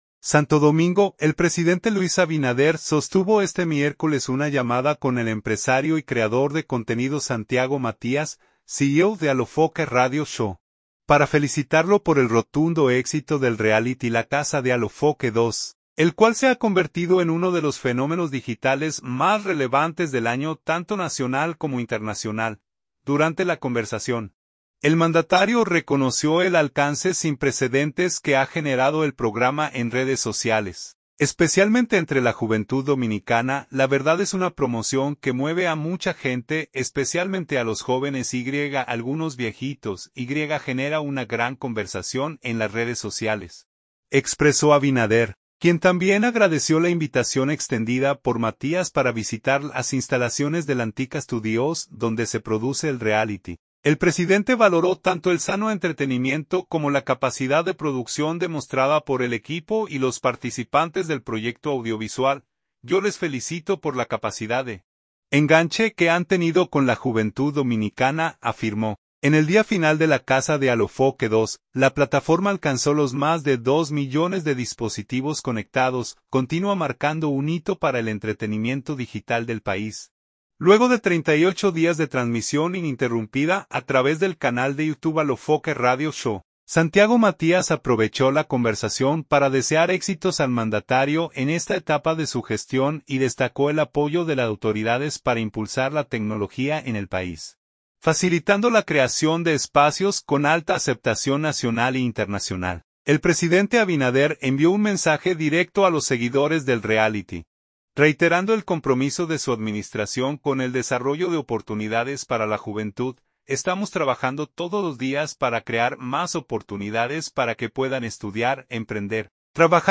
Santo Domingo.– El presidente Luis Abinader sostuvo este miércoles una llamada con el empresario y creador de contenido Santiago Matías, CEO de Alofoke Radio Show, para felicitarlo por el rotundo éxito del reality La Casa de Alofoke 2, el cual se ha convertido en uno de los fenómenos digitales más relevantes del año tanto nacional como internacional.